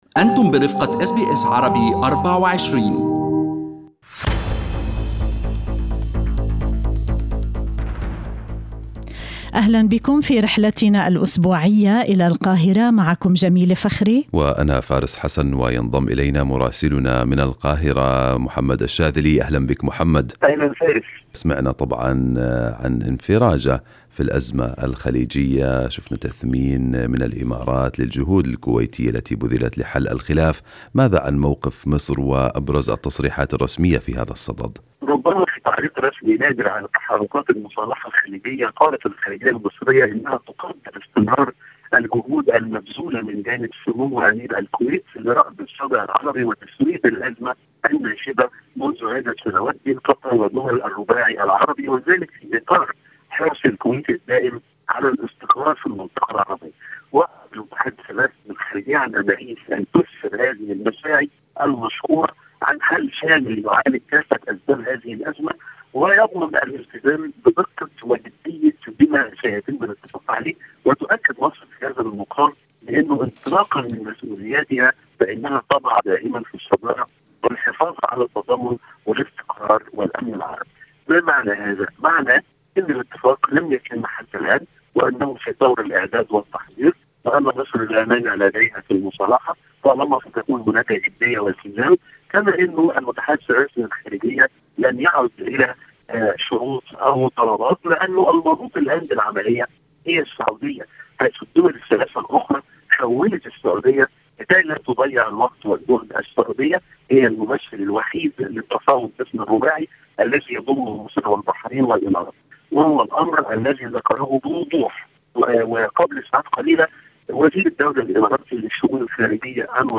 يمكنكم الاستماع إلى تقرير مراسلنا في القاهرة بالضغط على التسجيل الصوتي أعلاه.